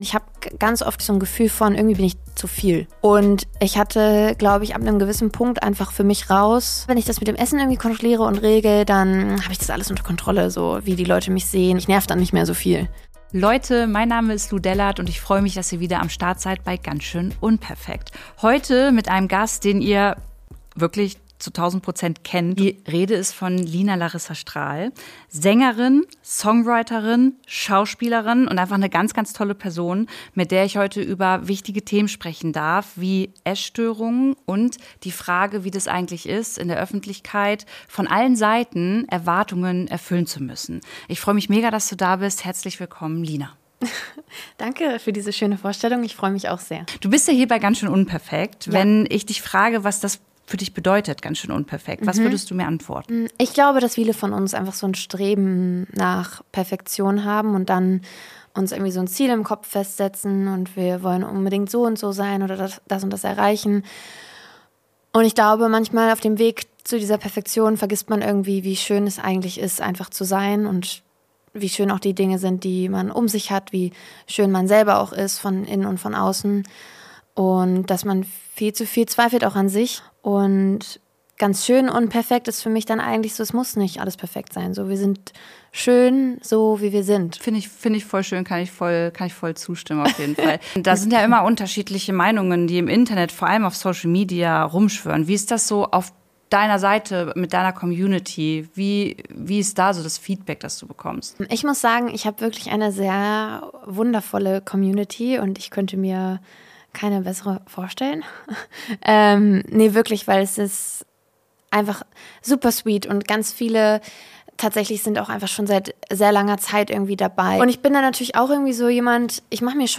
Sie teilt, wie frühe öffentliche Bekanntheit und der damit verbundene Erwartungsdruck ihr Selbstbild geprägt haben – und warum es für sie bis heute tägliche Arbeit ist, sich im eigenen Körper wohlzufühlen und ein entspanntes Verhältnis zu Essen zu haben. Ein ehrliches Gespräch über Selbstwert, Heilung und den Mut, Schwäche zu zeigen.